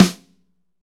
Index of /90_sSampleCDs/Northstar - Drumscapes Roland/DRM_Pop_Country/SNR_P_C Snares x